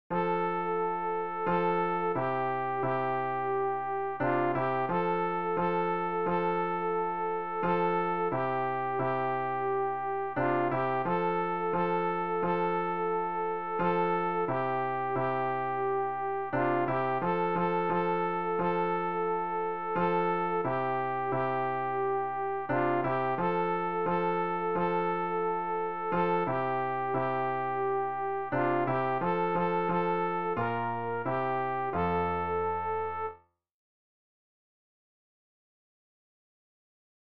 sopran-rg-289-unser-vater-im-himmel-ostkirchlich.mp3